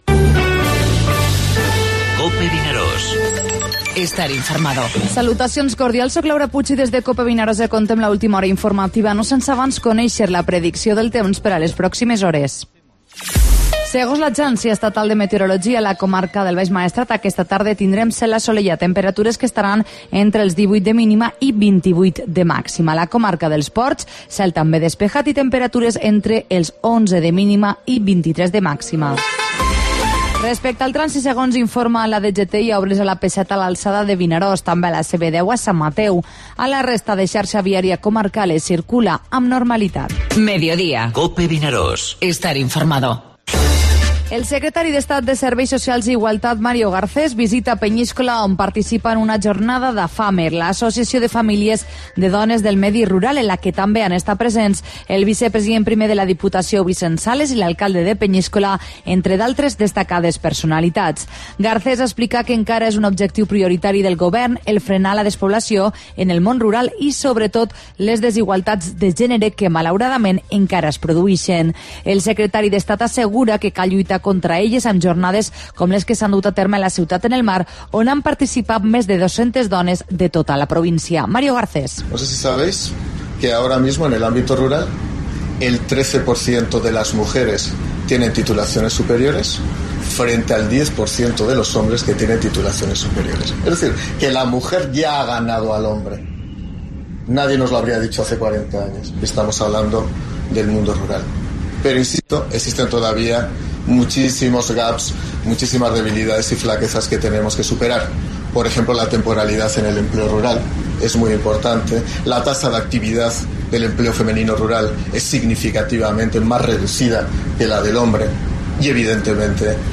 Informativo Medíodía COPE al Maestrat (07/09/2017)